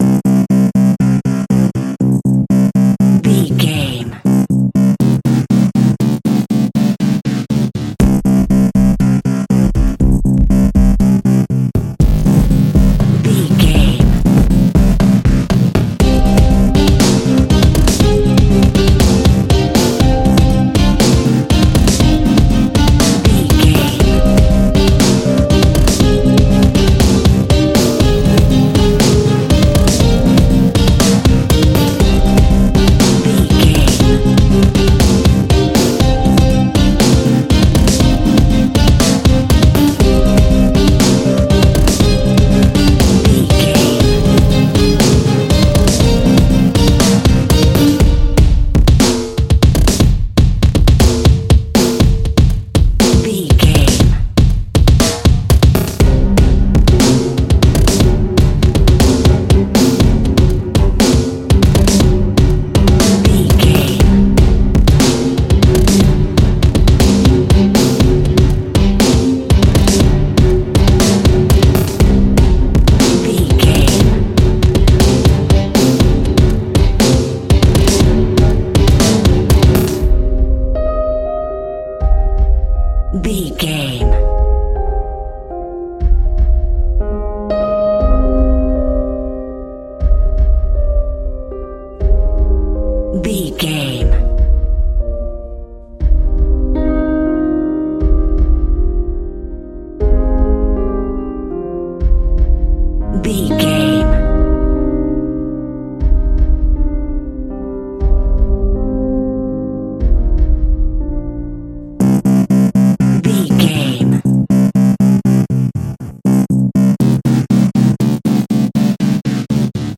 Aeolian/Minor
strings
percussion
synthesiser
brass
violin
cello
double bass